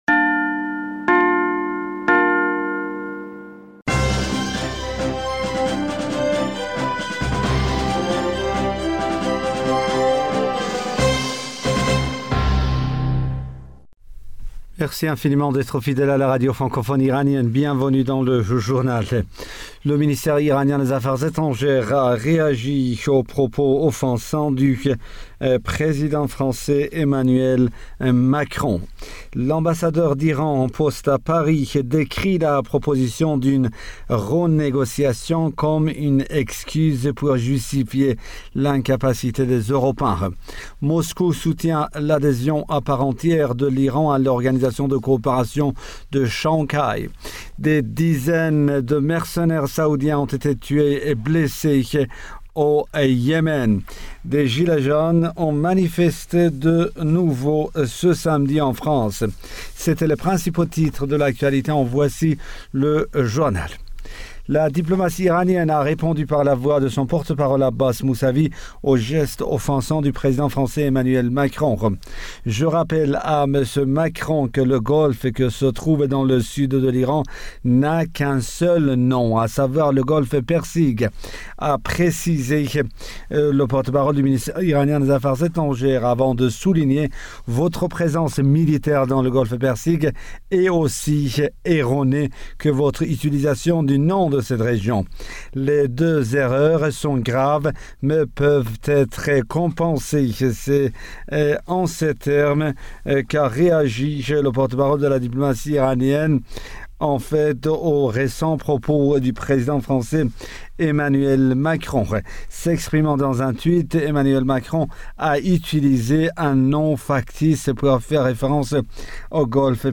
Bulletin d'information du 19 janvier 2020